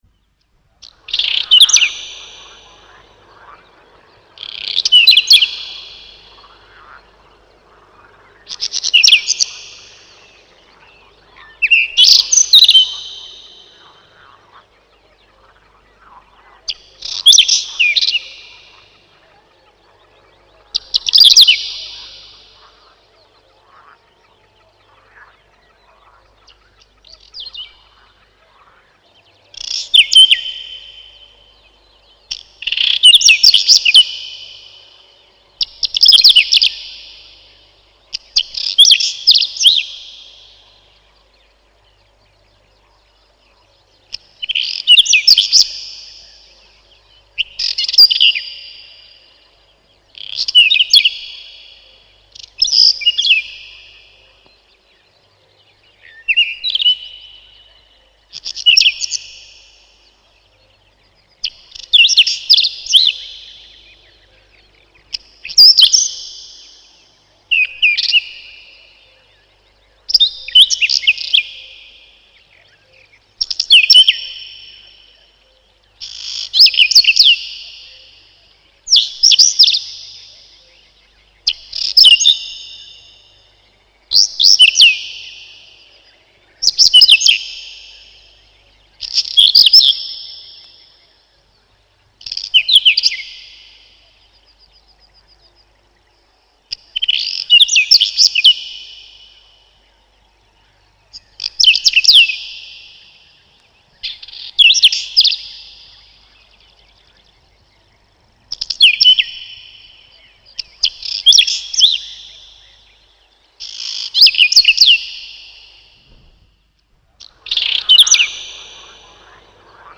Назад в Пение птиц
Файл 21 Луговой чекан (Saxicola rubetra).mp3
Луговой чекан — Saxicola rubetra
Голос.Песня — короткая щебечущая трель, при тревоге покачивает хвостом и кричит “туи-чек-чек”.
Это короткий и неясный по тонам быстрый говорок или щебетанье, напоминающее песенку серой славки, но менее звучное. Но если к песенке прислушаться внимательно, то в ней можно услышать голоса других птиц.